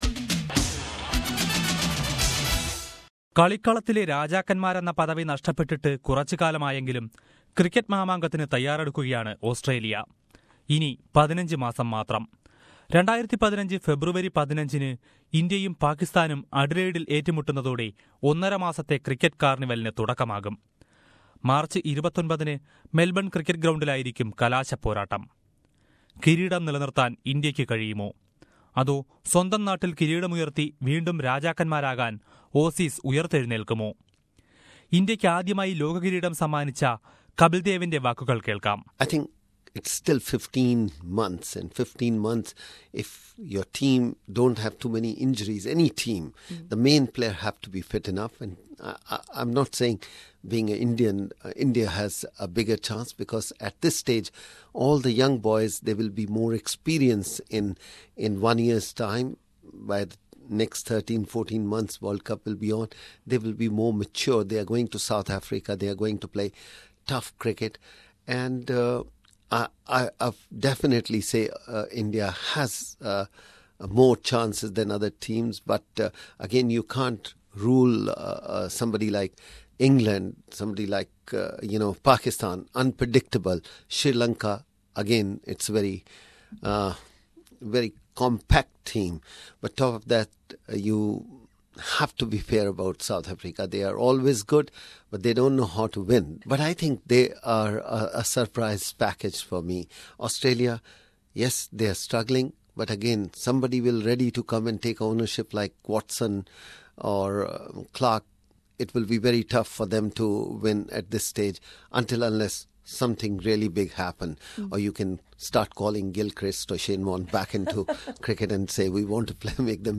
The Haryana Hurricane, Kapil Dev, who was in Melbourne last week, shared his thoughts and memories with SBS Punjabi..